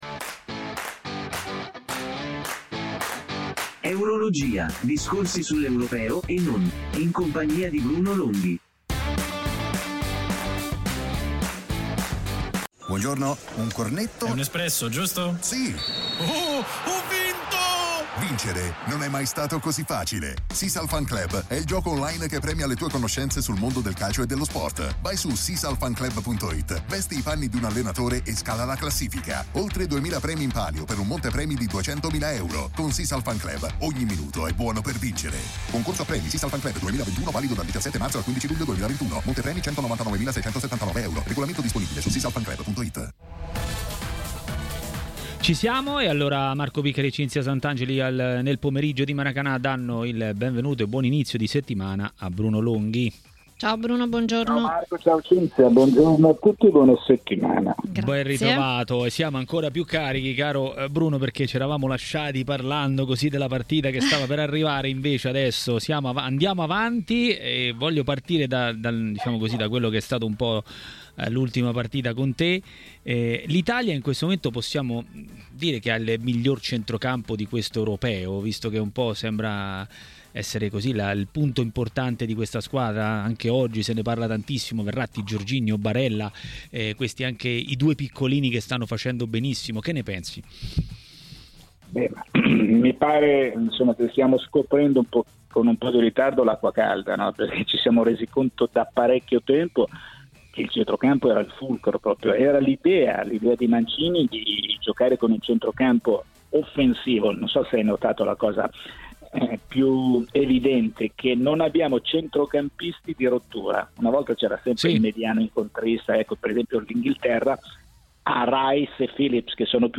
A TMW Radio, per "Eurologia", il giornalista Bruno Longhi ha detto la sua sugli Europei 2020.